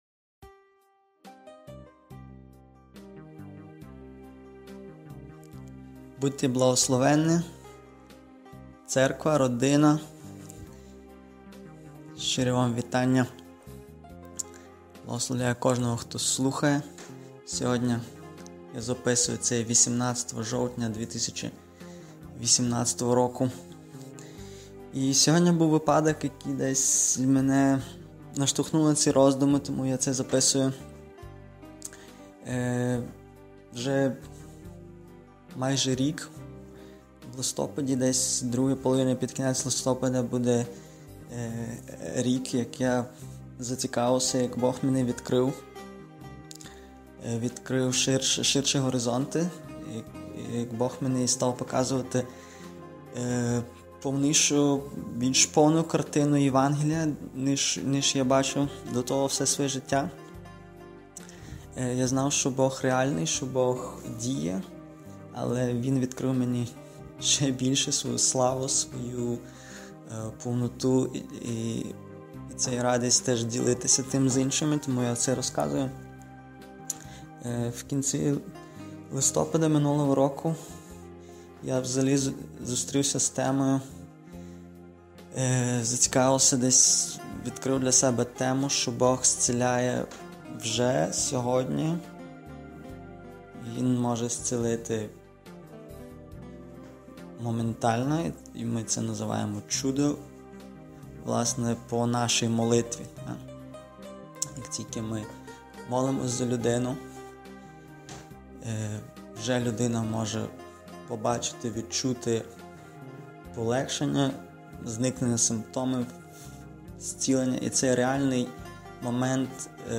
Проповіді